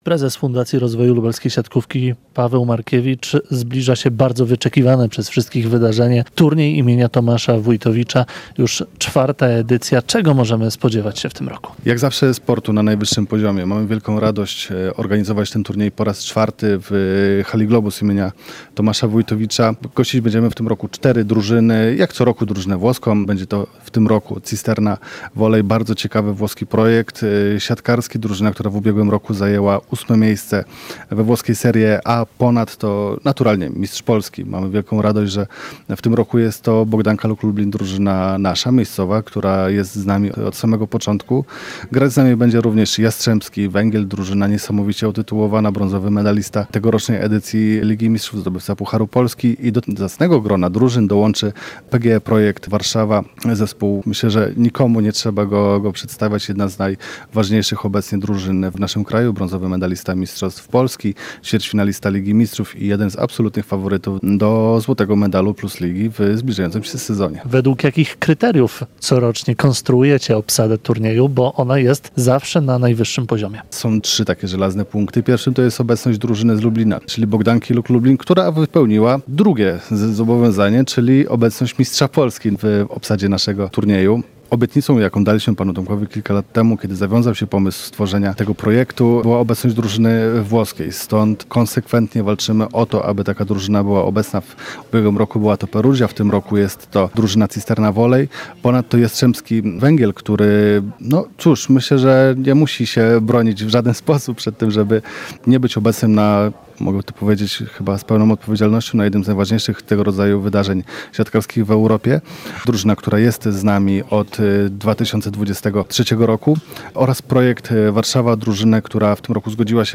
O szczegółach jednego z najbardziej wyczekiwanych turniejów towarzyskich w Europie organizatorzy zawodów poinformowali w piątek (01.08) w Lubelskim Centrum Konferencyjnym.